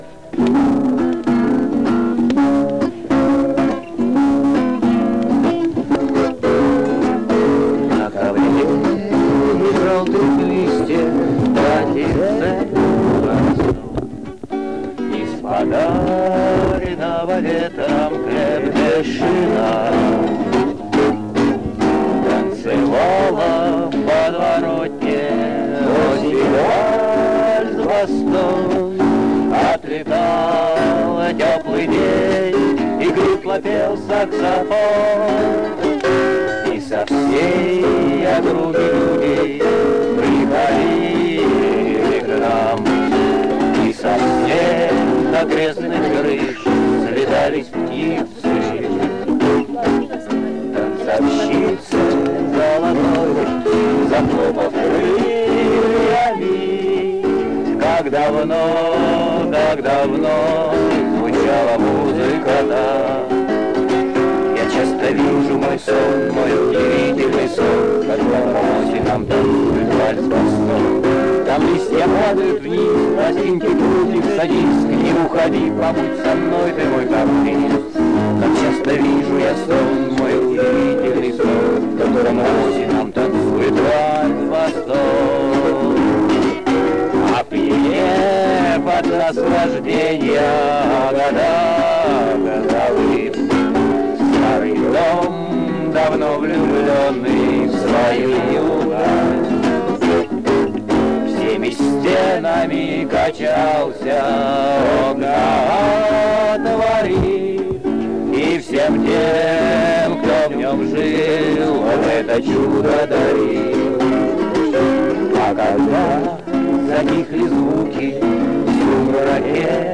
Слёт КСП, Московский куст «Беляево», лето 1986 года, сторона А.
Эти аудиофайлы отличаются ужасным качеством и арранжировкой. Записаны вашим покорным слугой в помосковном лесу, под дождём, на каасетный магнитофон советского производства, с микрофона, прикрученного изолентой к сосновой ветке.